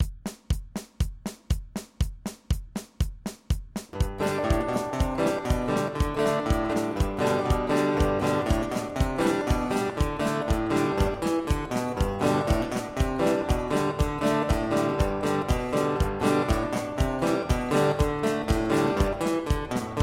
Piste guitare (midi) tablature midi